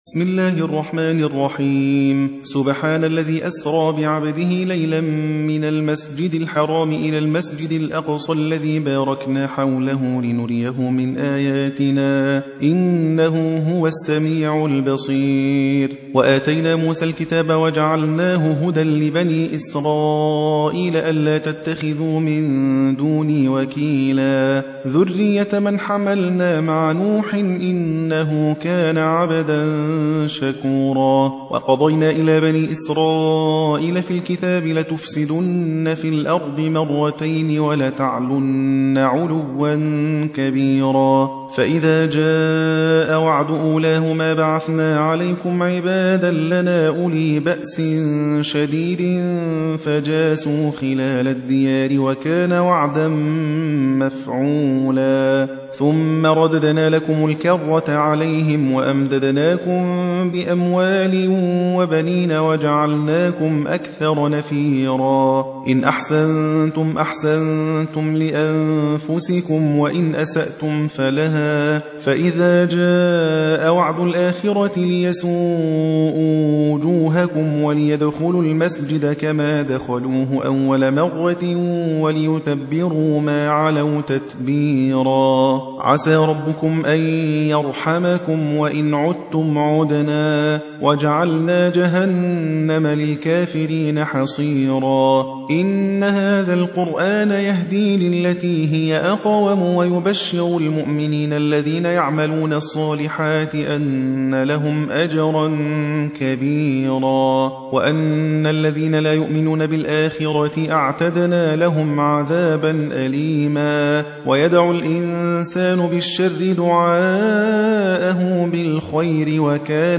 17. سورة الإسراء / القارئ